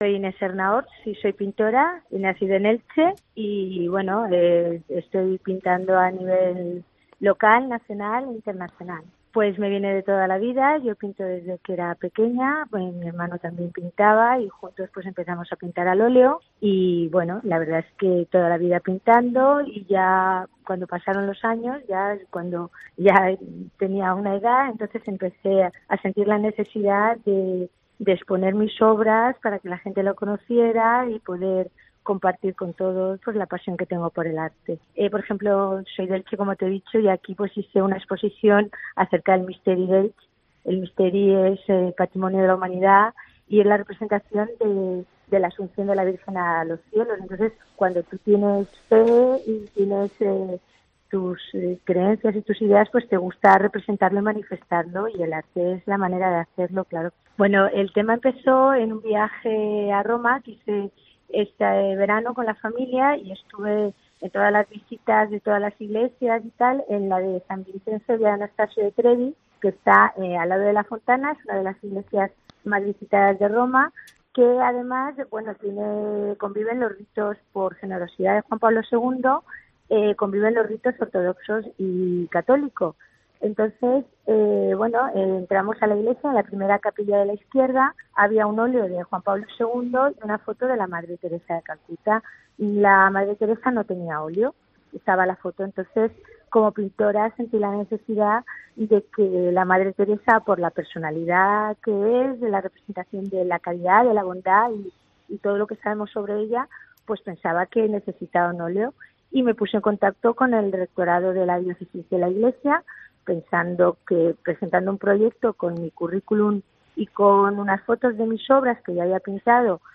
En una entrevista para Religión COPE nos ha contado que su especialidad son el óleo y los retratos.